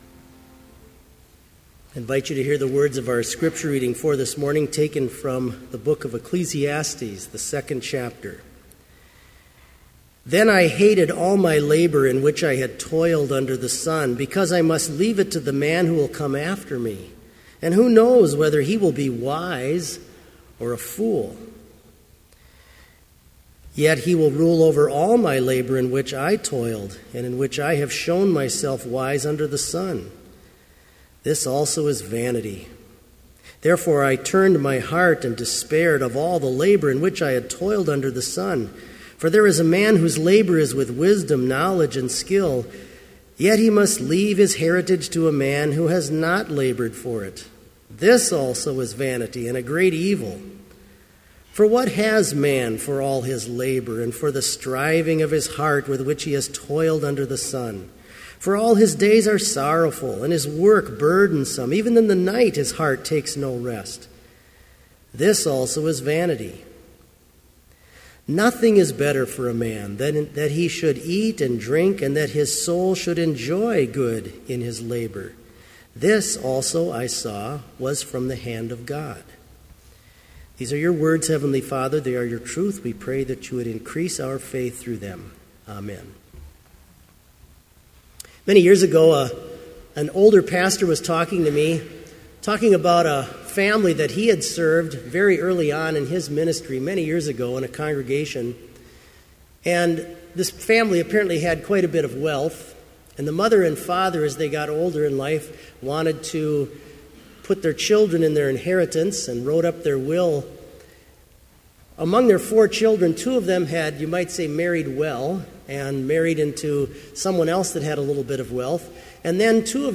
Sermon audio for Chapel - March 20, 2015